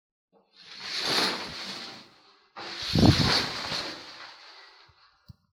Sacudiendo y estirando las sábanas de la cama
Grabación sonora en la se escucha el sonido de unas sábanas al ser sacudidas.
Sonidos: Hogar